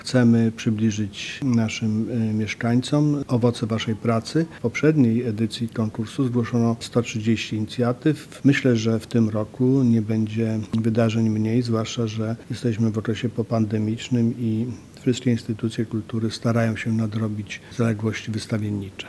– Chcemy przybliżyć naszym mieszkańcom owoce waszej pracy. W poprzedniej edycji konkursu zgłoszono 130 inicjatyw. Myślę, że w tym roku nie będzie ich mniej zwłaszcza, że jesteśmy w okresie po pandemicznym i wszystkie instytucje kultury starają się nadrobić zaległości wystawiennicze – mówi marszałek Mazowsza Adam Struzik.